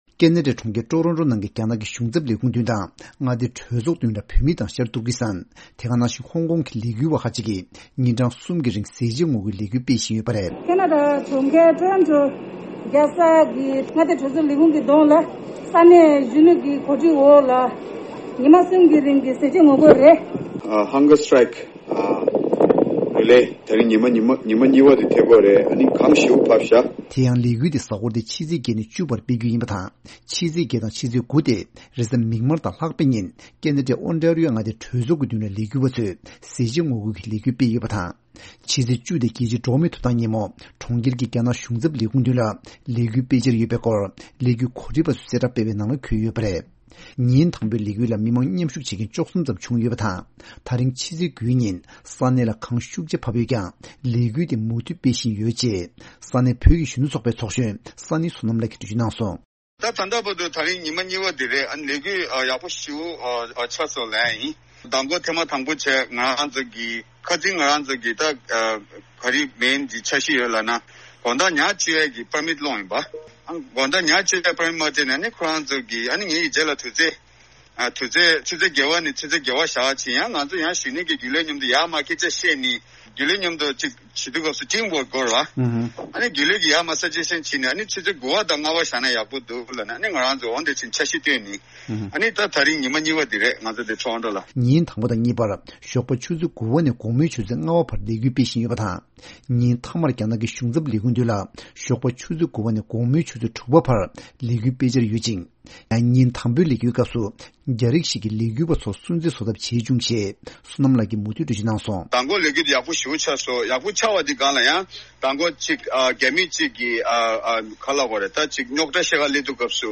གནས་ཚུལ་སྙན་སྒྲོན་ཞུ་ཡི་རེད།།